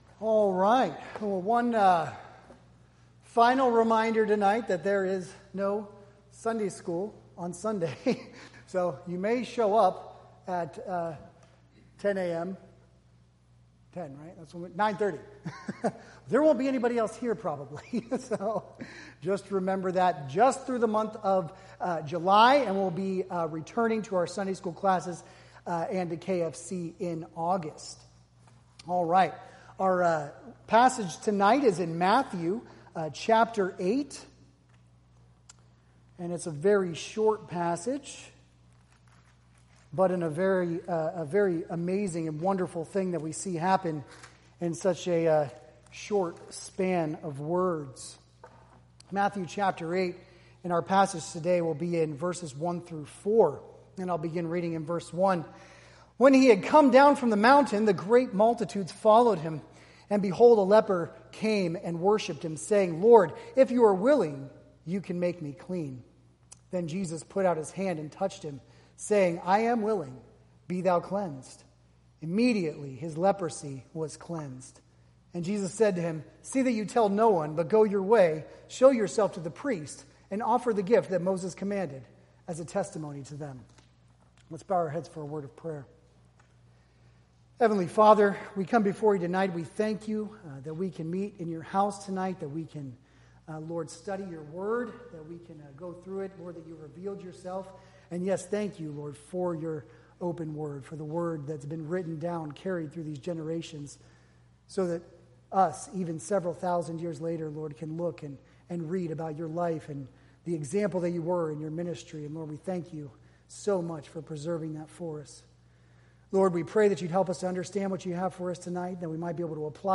Matthew Passage: Matthew 8:1-4 Service Type: Wednesday Evening Topics